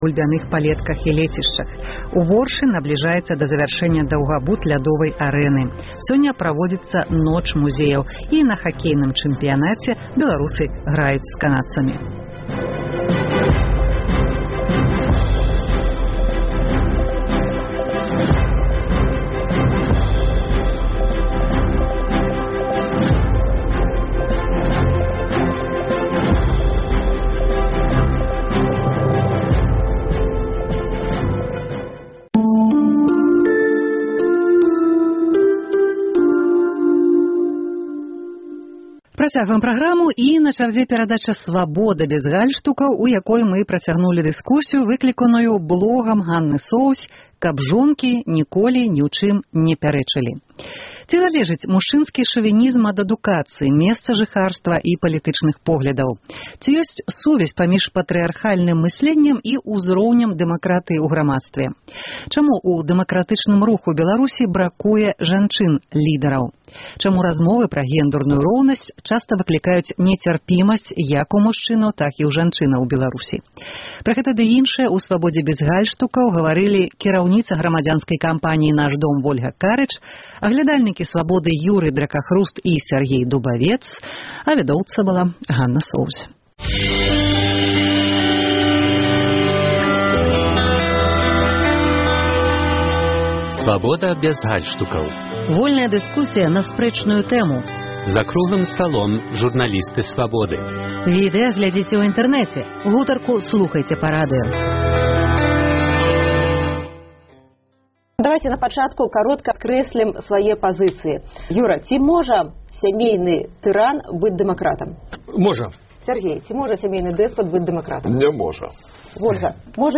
У перадачы «Свабода бяз гальштукаў» мы працягнулі дыскусію